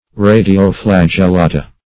Search Result for " radio-flagellata" : The Collaborative International Dictionary of English v.0.48: Radio-flagellata \Ra`di*o-flag`el*la"ta\ (r[=a]`d[i^]*[-o]*fl[a^]j`[e^]l*l[=a]"t[.a]), n. pl.